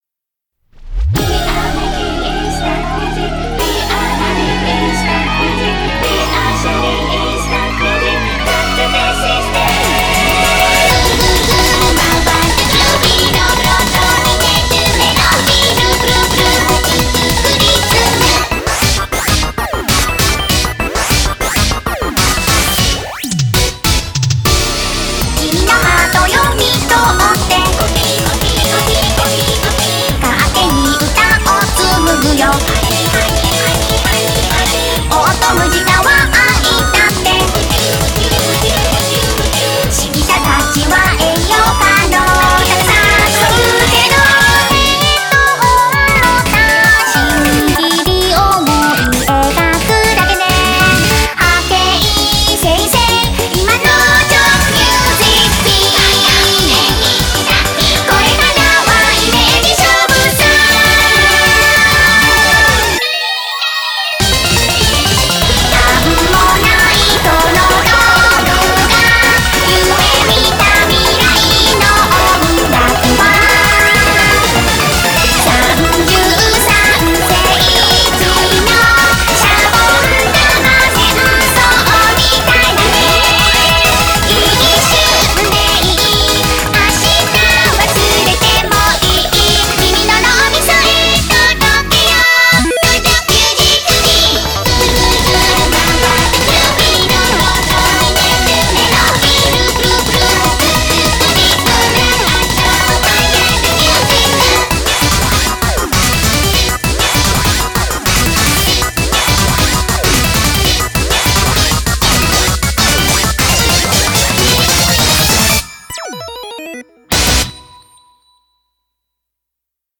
BPM49-197
Audio QualityPerfect (Low Quality)